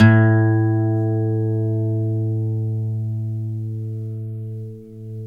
GTR 6STR A03.wav